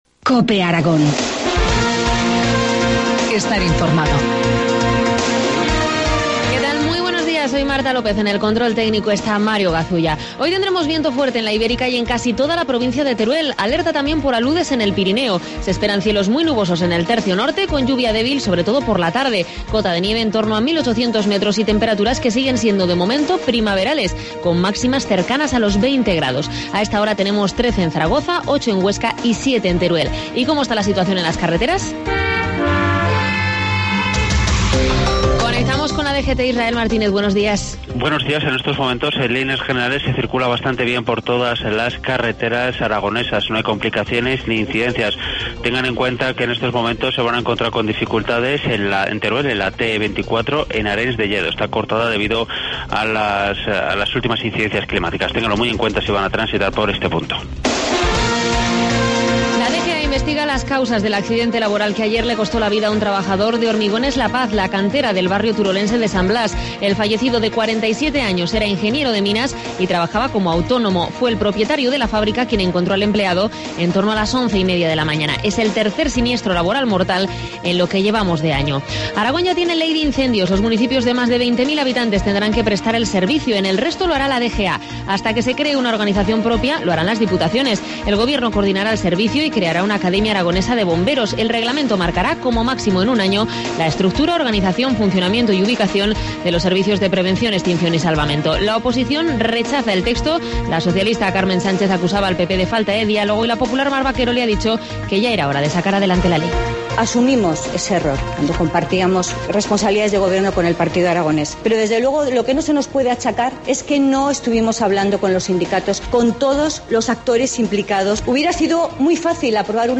Informativo matinal, viernes 8 de marzo, 7.25 horas